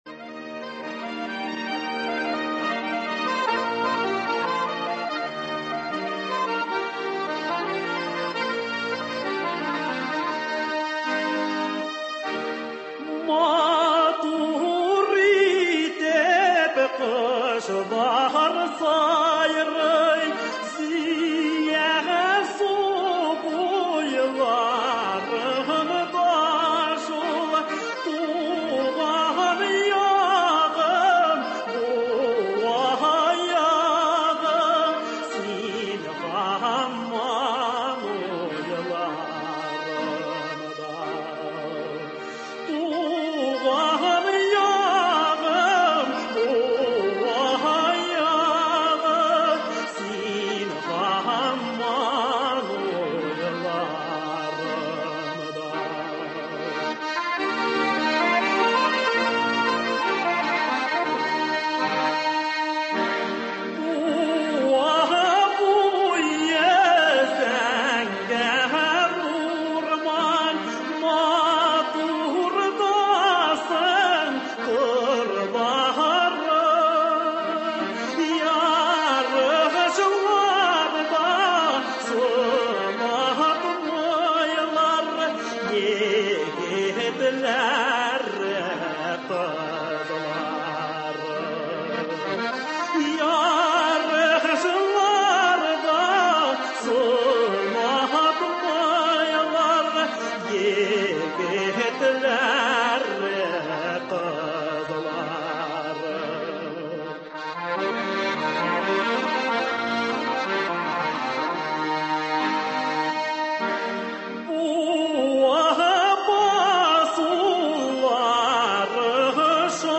Туры эфир (14.11.22)